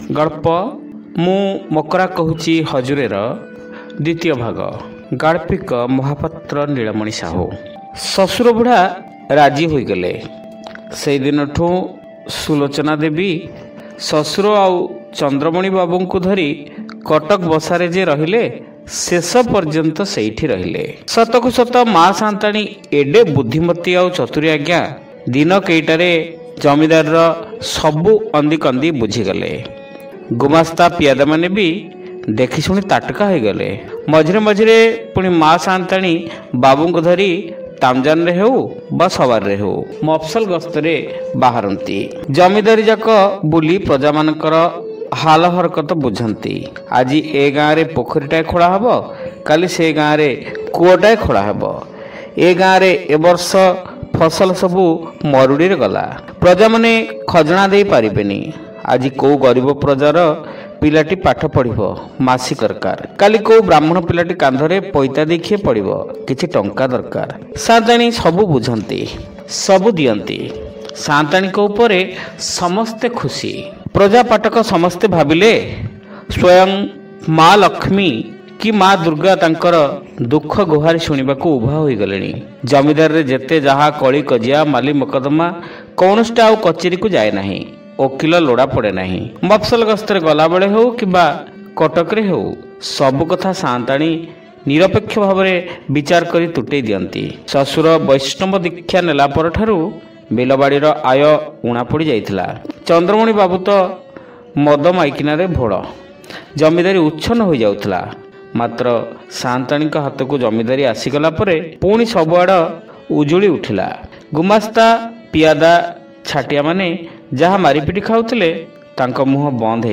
ଶ୍ରାବ୍ୟ ଗଳ୍ପ : ମୁଁ ମକରା କହୁଛି ହଜୁରେ (ଦ୍ୱିତୀୟ ଭାଗ)